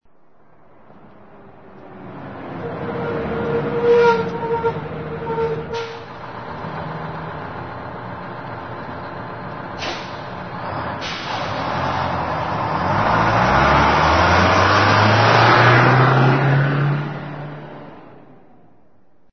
PARADA AUTOBUS BUS1 STOP
Ambient sound effects
Parada_autobus_BUS1_stop.mp3